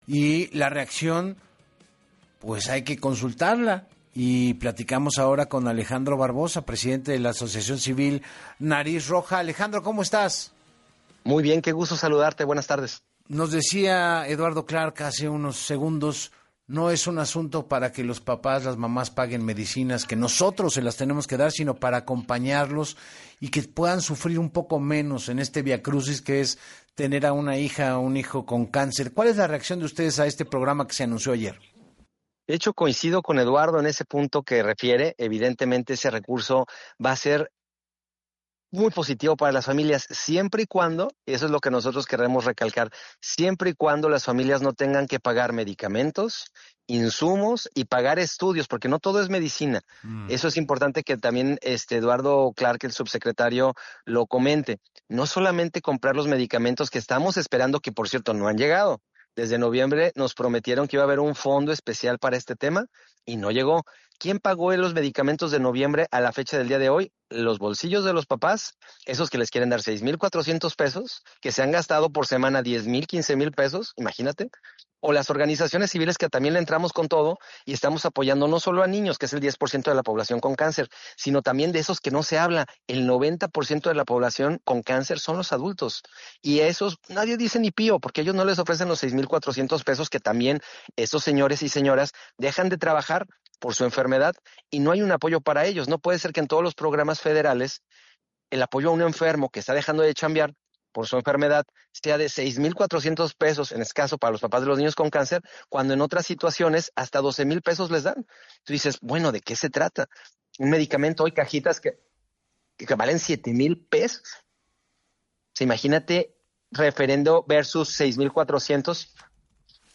En entrevista para Así Las Cosas con Enrique Hernández Alcázar